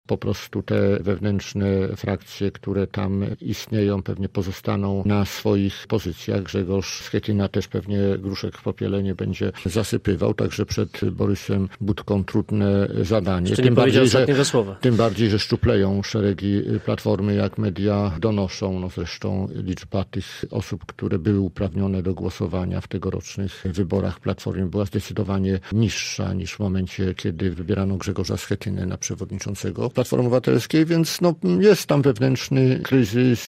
W Rozmowie Punkt 9 Marek Ast wyrażał wątpliwości, by sytuacja w Platformie uległa poprawie: